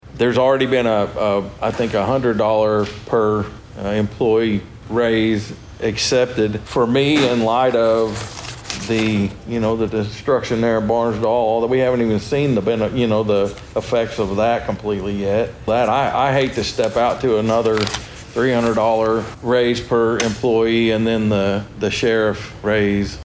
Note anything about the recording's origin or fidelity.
At Monday's Board of Osage County Commissioners meeting, there was discussion regarding a revised sheriff's office budget for the 2024-2025 fiscal year.